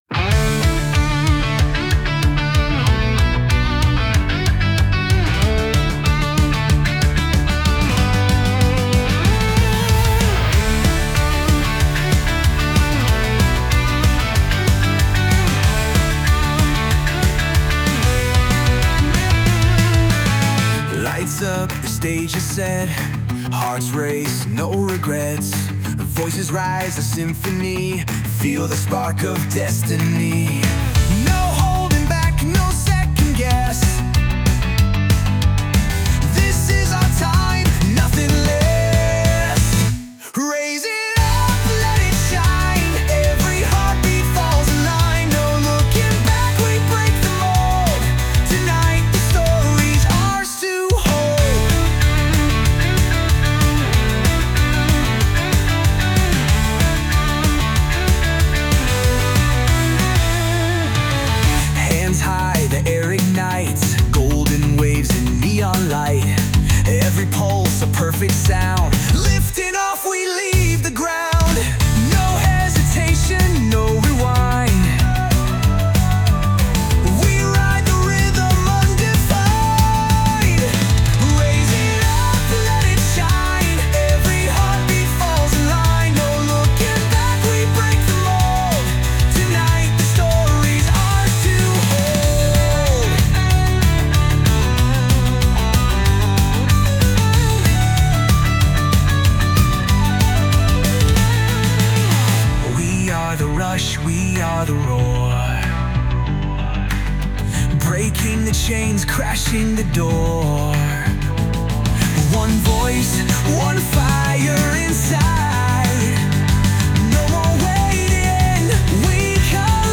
著作権フリーオリジナルBGMです。
男性ボーカル（洋楽・英語）曲です。
お祝いロックをテーマに制作しました！
歌詞はそこまでそれっぽくはないですが、良い感じにアップテンポでハイテンションに仕上がったと思います✨